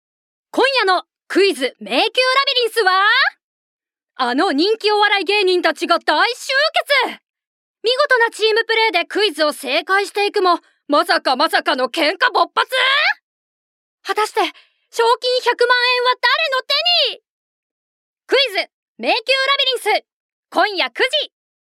◆お店紹介ナレーション◆
◆バラエティナレーション◆